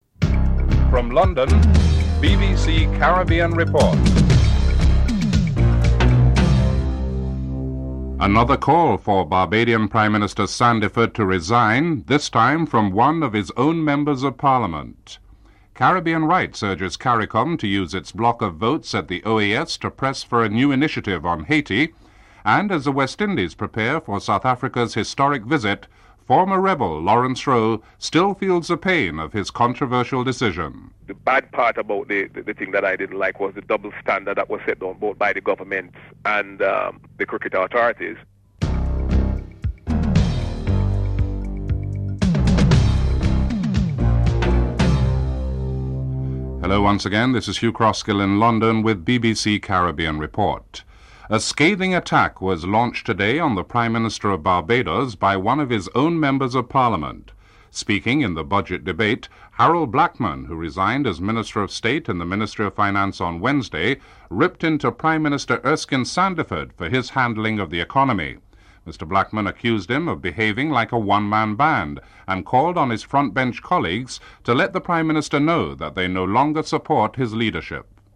1. Headlines (00:00-00:45)
However, Secretary General of the OAS Joâo Baena Soares speaking in an interview, rules out taking the crisis to the United Nations (05:53-07:31)